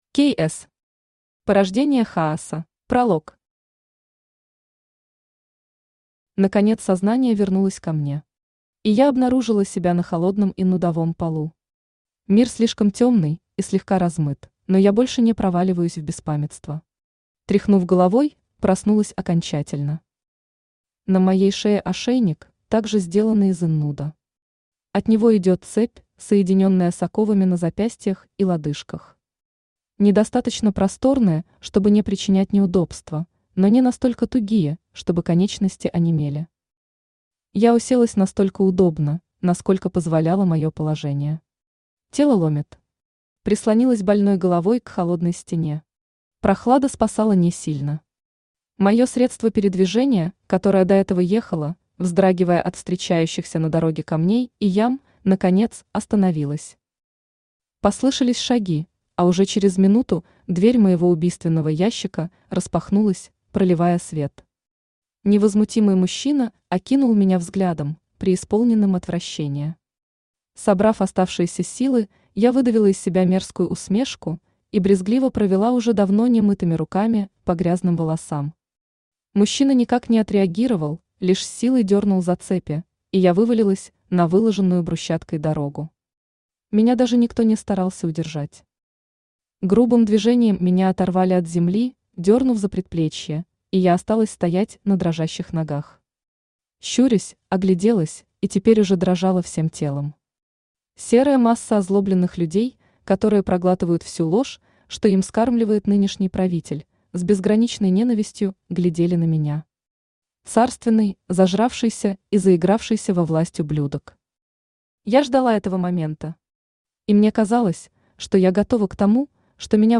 Аудиокнига Порождение Хаоса | Библиотека аудиокниг
Aудиокнига Порождение Хаоса Автор K S Читает аудиокнигу Авточтец ЛитРес.